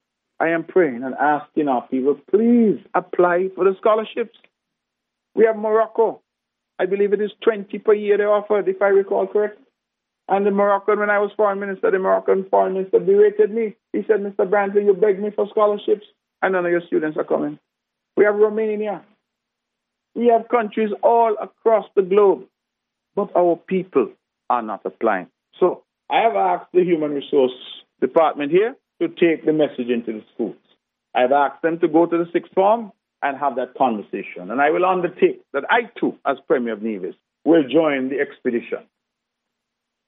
During his monthly press conference on August 28th, Mr. Brantley discussed that Nevisian youth should take advantage of the available scholarship opportunities and cited reasons such as free, high quality education, a new language and that there is no massive debt to repay from student loans.